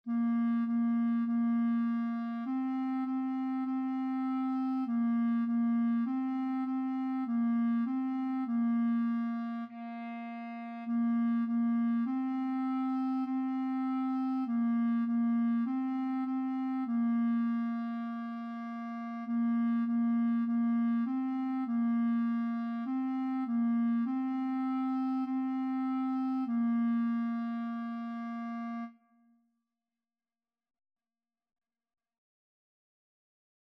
4/4 (View more 4/4 Music)
Bb4-C5
Clarinet  (View more Beginners Clarinet Music)
Classical (View more Classical Clarinet Music)